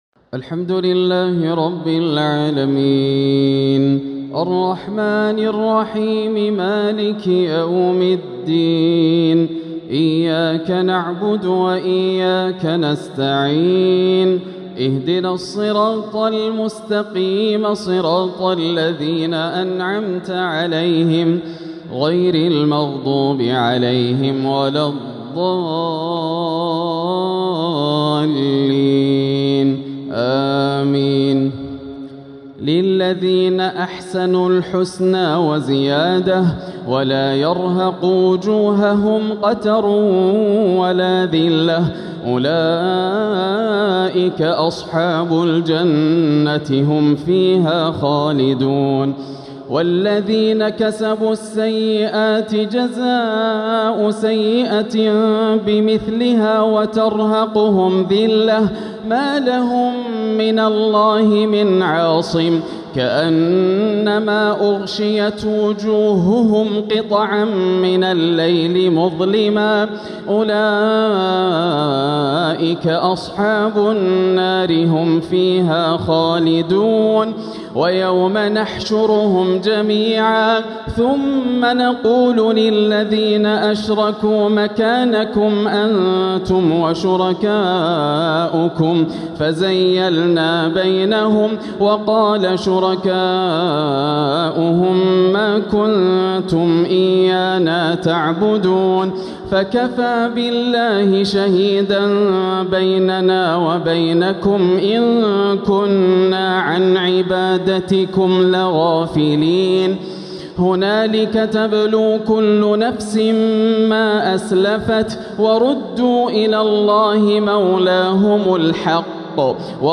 تلاوة بديعة في ترتيلها جميلة في تحبيرها تراويح ليلة ١٥ رمضان ١٤٤٧ هـ > الليالي الكاملة > رمضان 1447 هـ > التراويح - تلاوات ياسر الدوسري